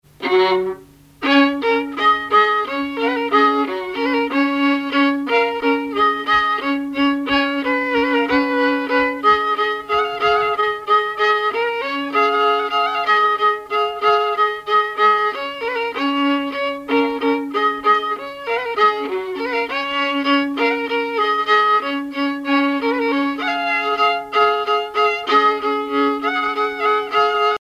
violoneux, violon,
musique traditionnelle
Motte-en-Champsaur (La)
danse : valse